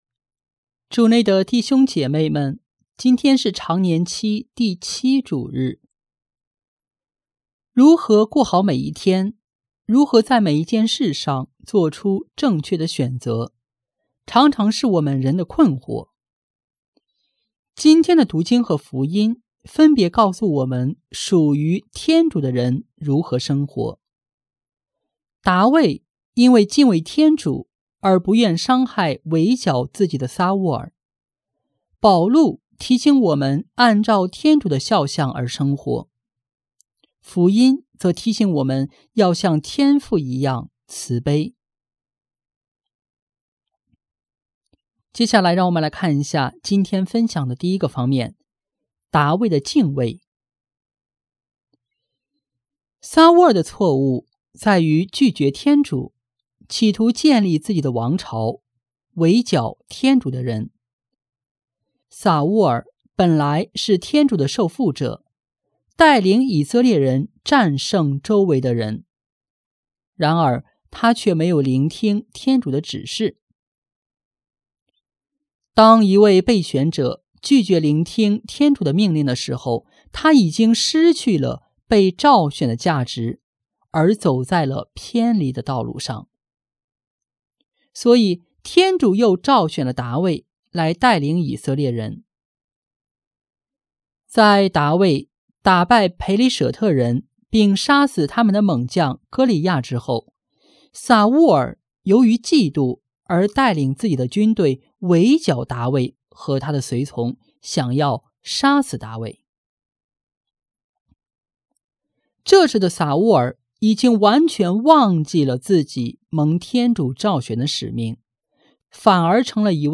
【主日证道】| 肖像慈悲心（丙-常年期第7主日）